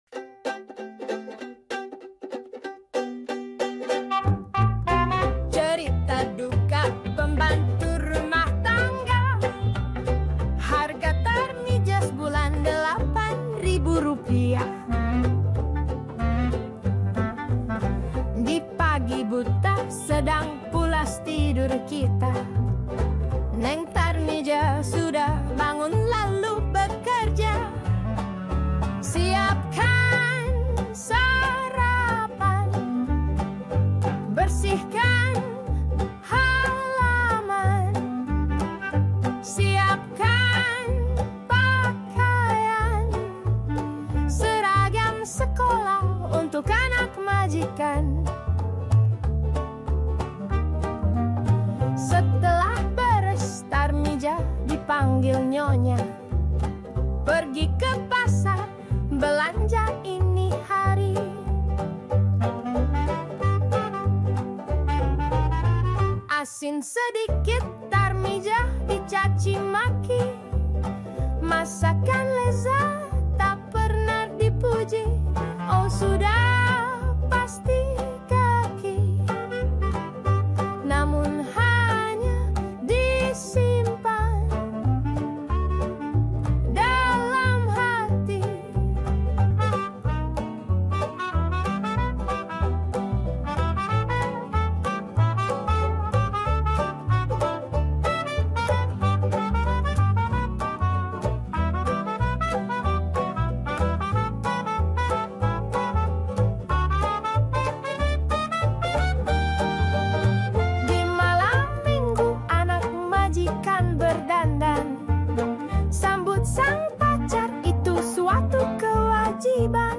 AI Cover